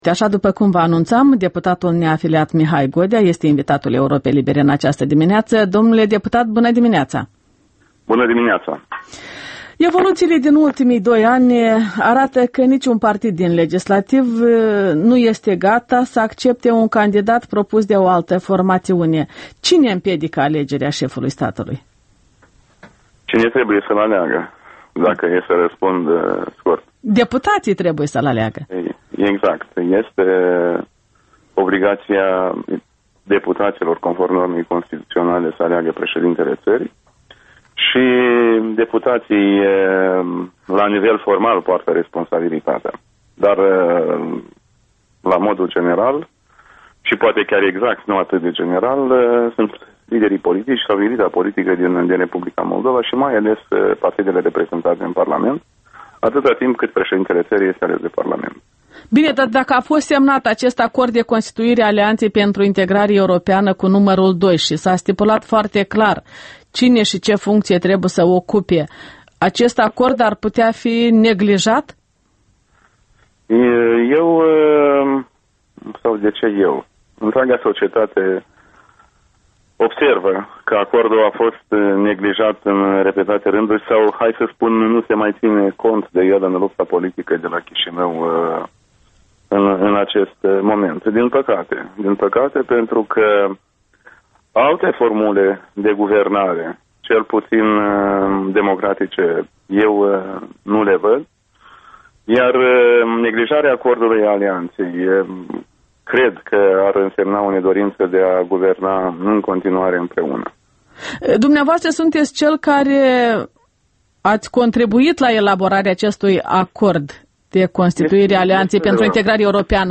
Interviul matinal cu Mihai Godea